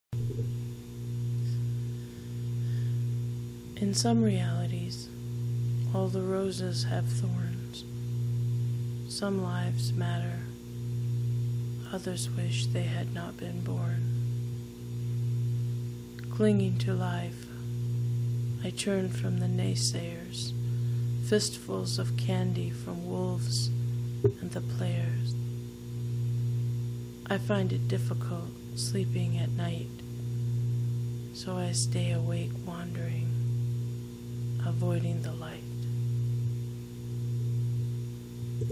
1. Record yourself reading one of your own works.